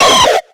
Cri de Griknot dans Pokémon X et Y.